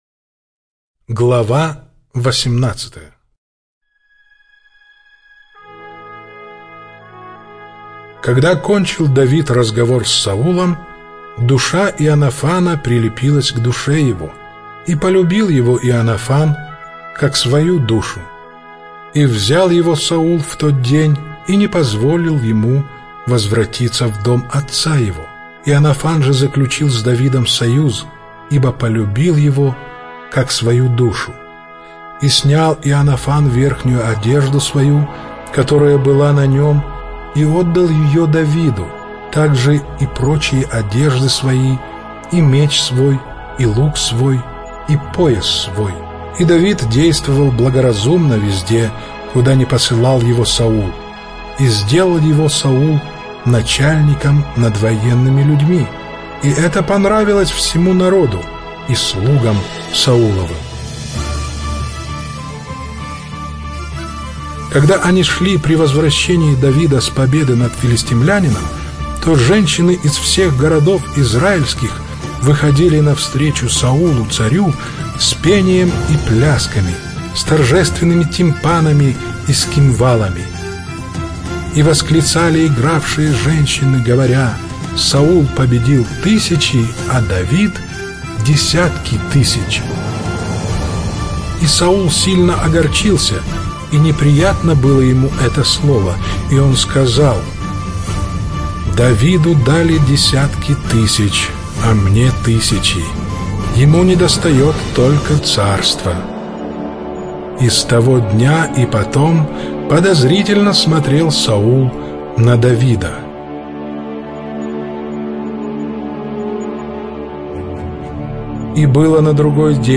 Слушать Первую книгу Царств с музыкой глава 18.
Чтение сопровождается оригинальной музыкой и стерео-эффектами